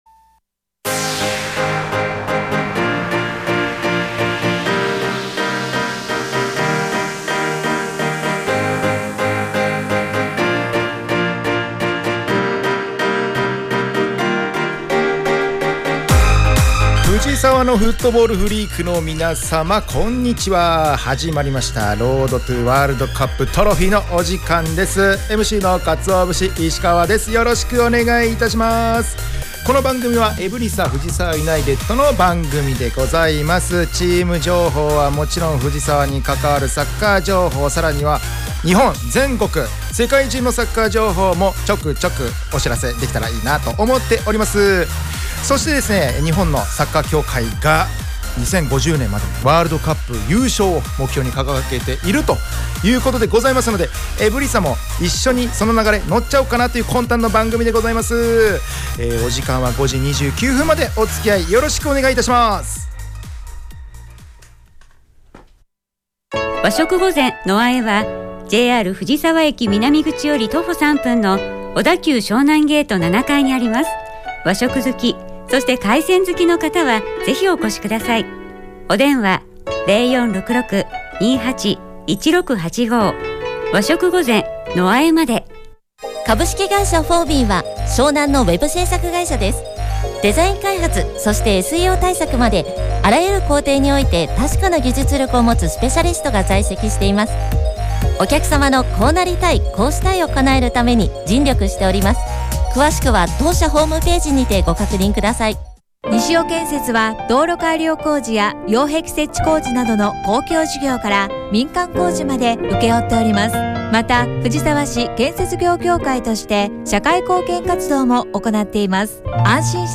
【ラジオ】Road to WC Trophy 2期 第55回放送 – エブリサ藤沢ユナイテッド
エブリサ藤沢ユナイテッドが提供する藤沢サッカー専門ラジオ番組『Road to WC Trophy』の第2期の第55回放送が4月18日(金)17時に行われました☆